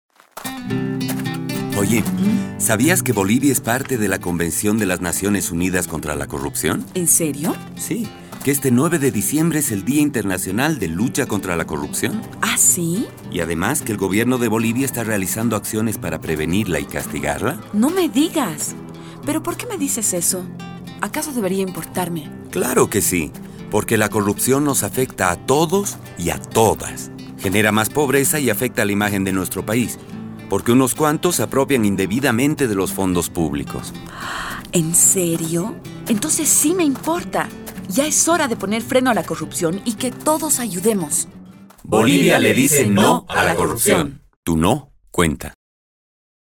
UNODC Bolivia and the Governement produced a radio spot to promote the United Nations Convention against Corruption, the International Anti-Corruption Day and the efforts to fight corruption in Bolivia.